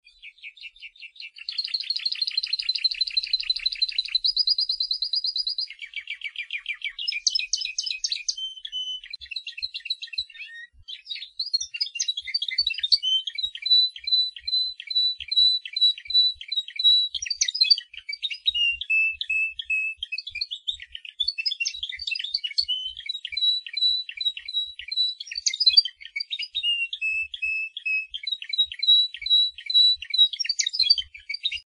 7. Свист